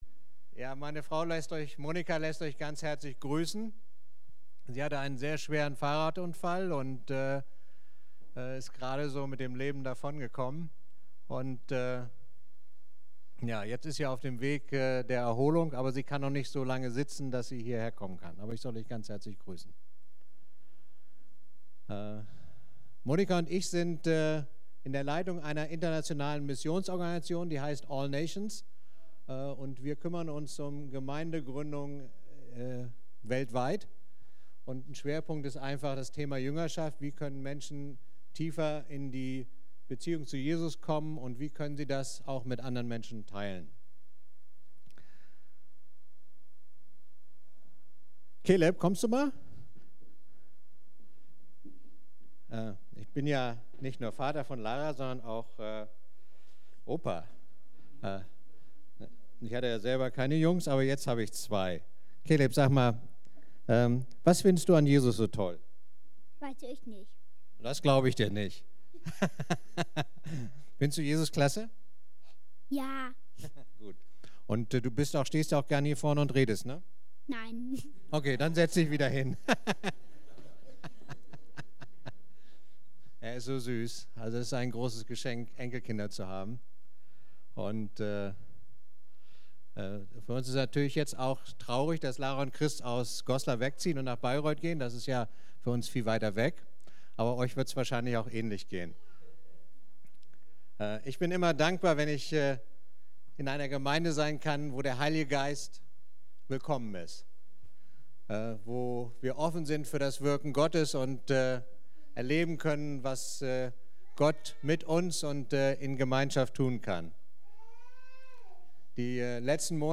Kirche am Ostbahnhof Navigation Infos Über uns…
Predigten